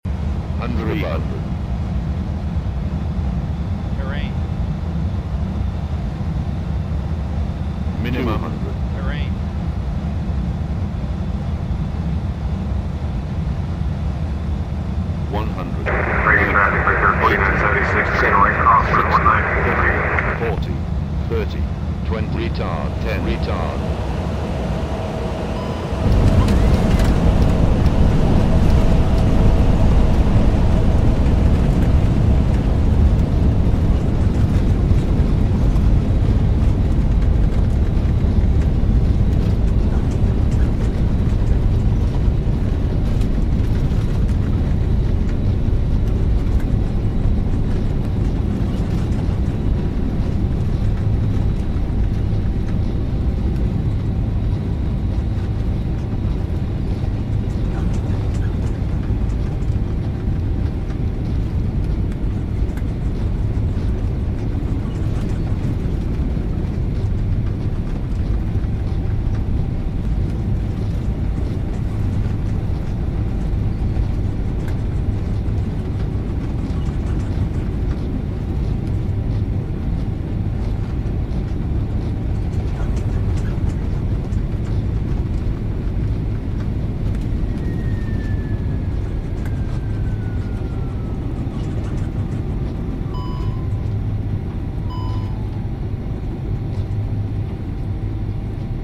A350 landing into KIAD. Flight sound effects free download